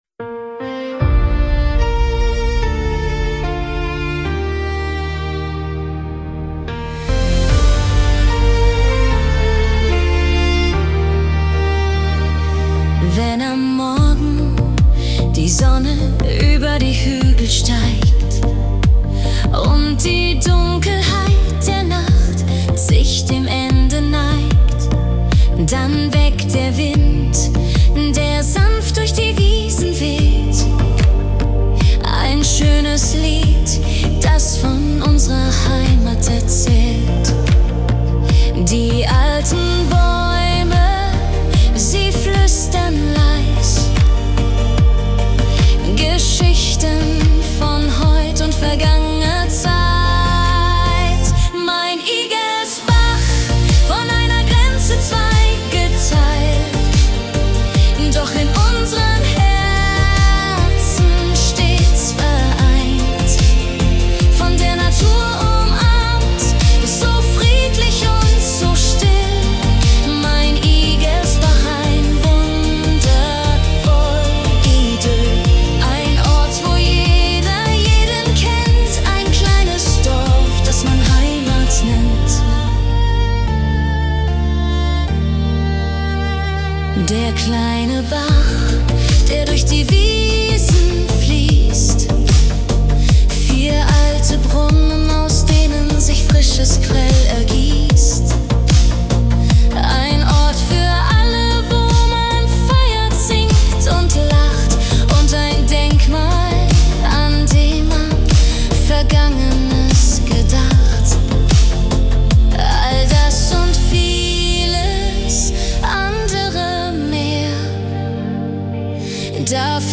Pop-Schlager Version